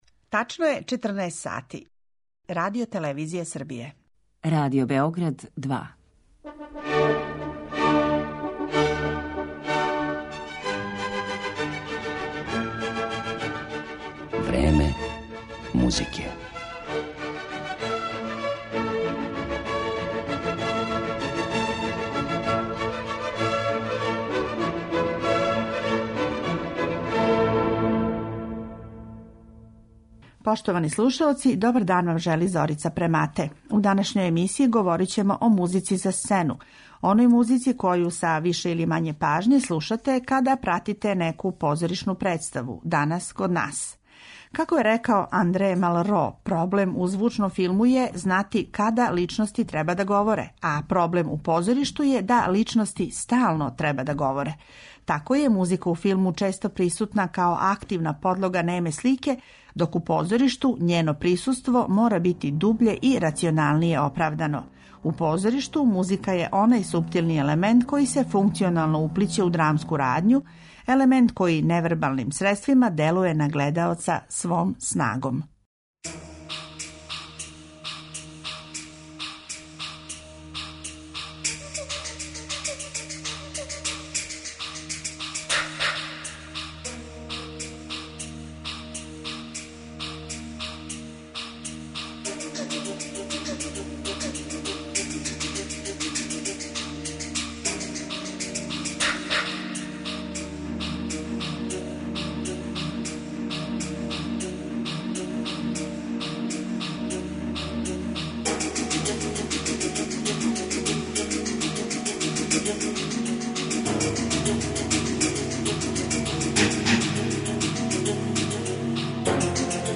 Савремени домаћи позоришни звук